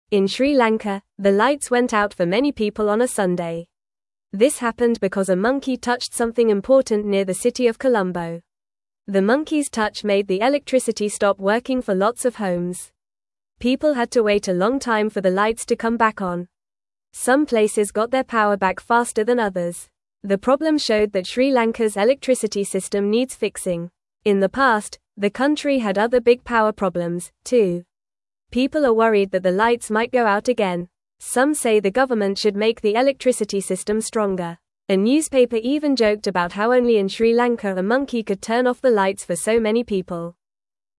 Fast
English-Newsroom-Beginner-FAST-Reading-Monkey-Turns-Off-Lights-in-Sri-Lanka-for-Everyone.mp3